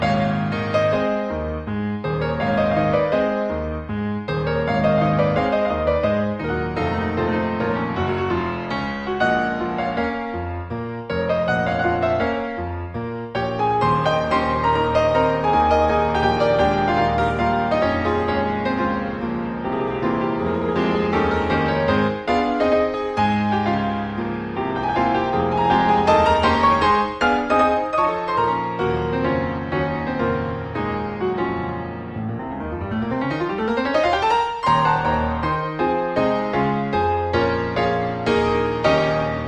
この部分です。This part of the music.